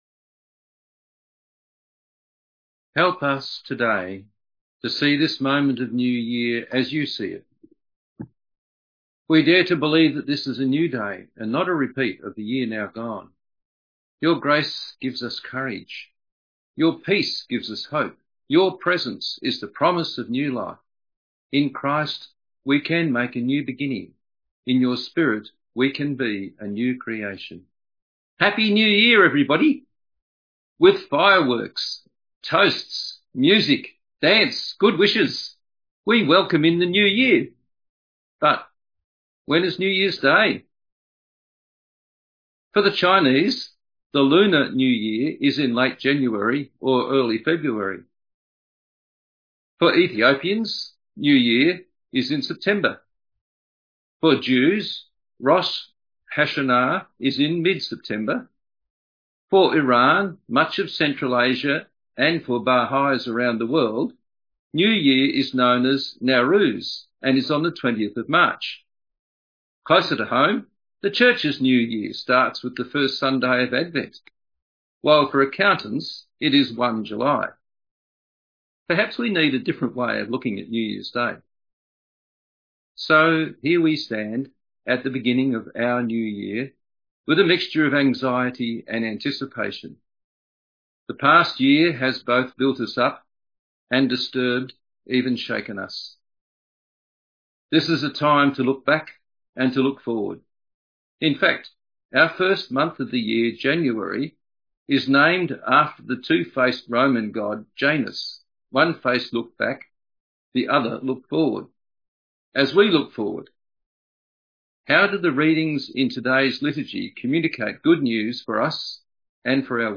A sermon on Jeremiah 31:7-14; Psalm 147:12-20; Ephesians 1:3-14 & John 1: 1-18